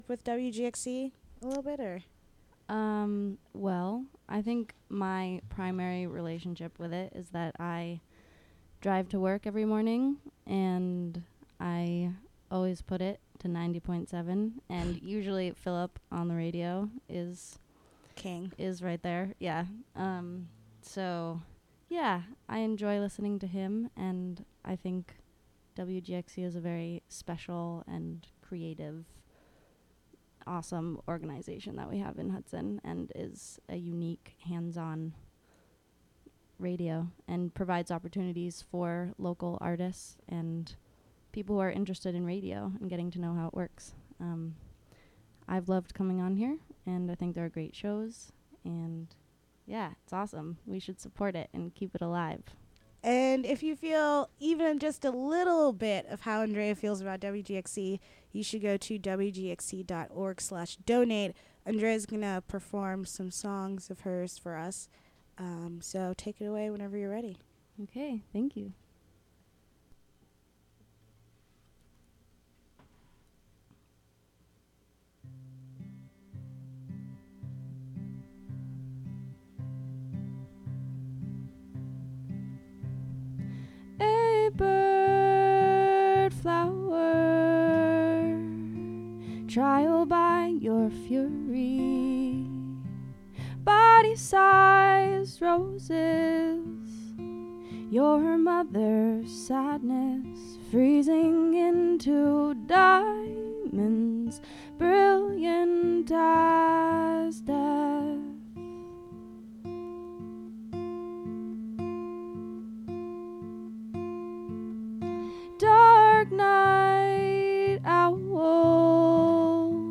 local Hudson Valley Singer/Songwriter performs on WGXC 90.7FM for WGXC's Monthly Sustaining Membership Drive.